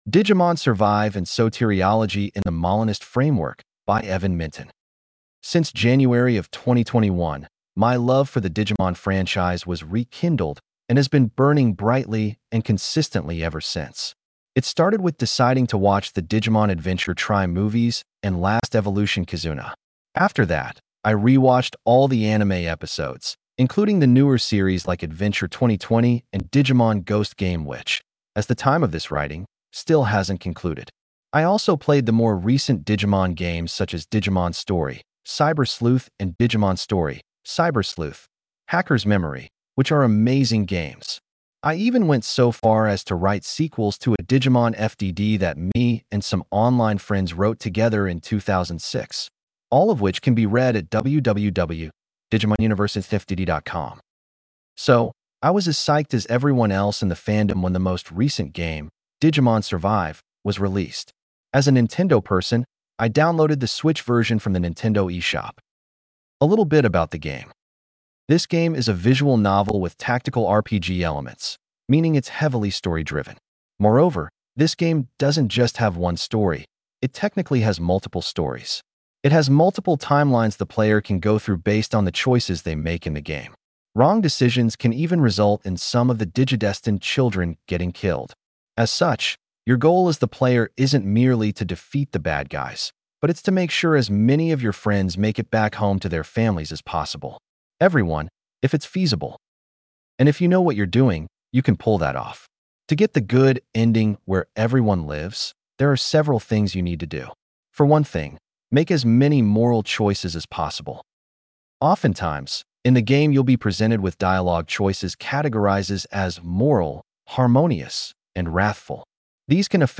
Audio accessibility for this blog post is powered by Microsoft Text-to-Speech technology.